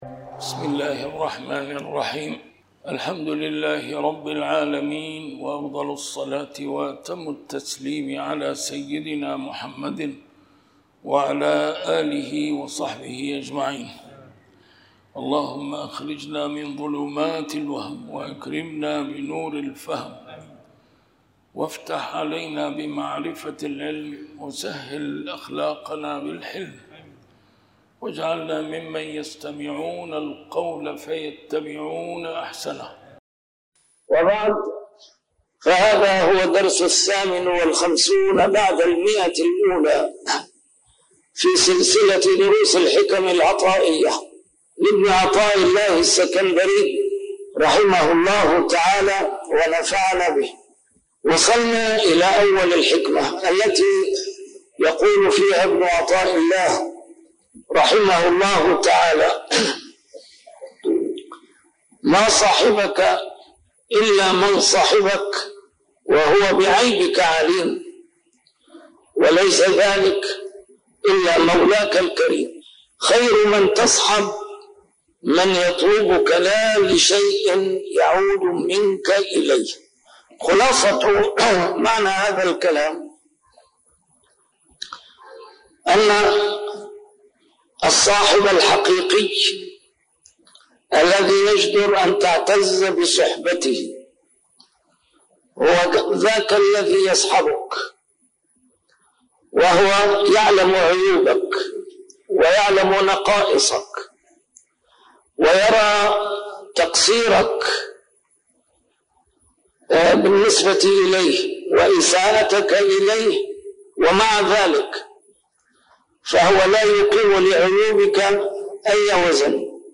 A MARTYR SCHOLAR: IMAM MUHAMMAD SAEED RAMADAN AL-BOUTI - الدروس العلمية - شرح الحكم العطائية - الدرس رقم 158 شرح الحكمة 135